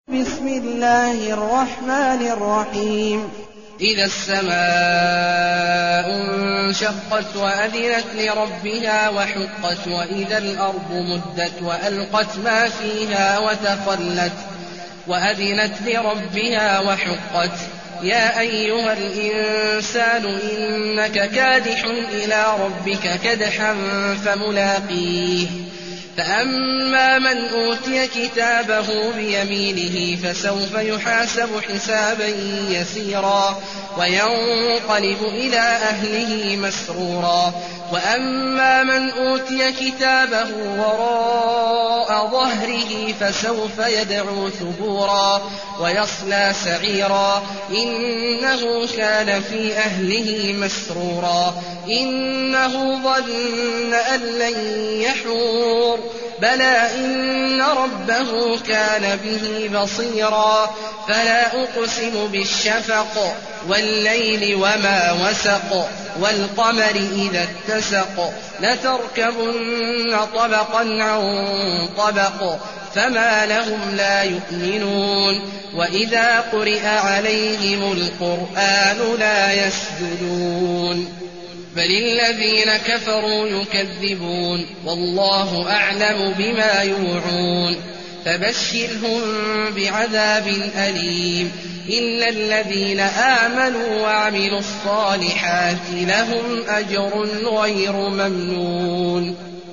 المكان: المسجد الحرام الشيخ: عبد الله عواد الجهني عبد الله عواد الجهني الانشقاق